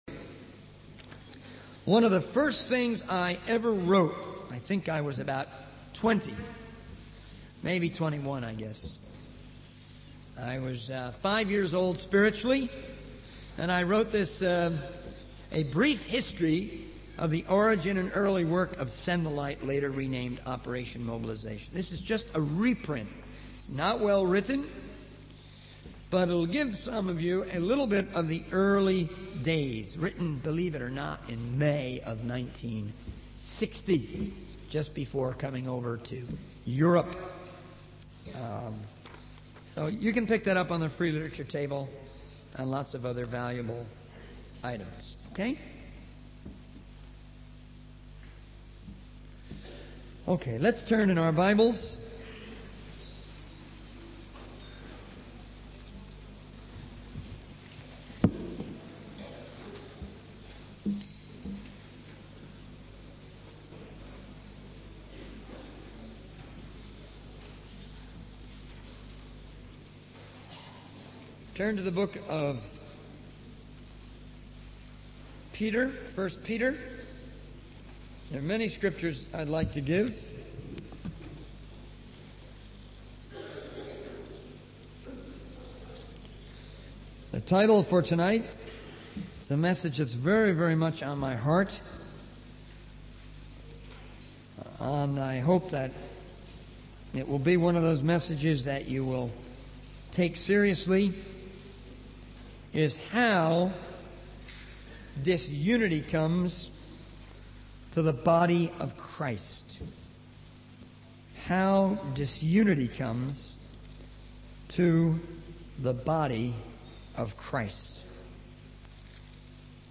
In this sermon, the speaker addresses the issue of disunity within the body of Christ. He emphasizes that disunity can arise from various factors such as overreaction, crooked thinking, and unrealistic expectations.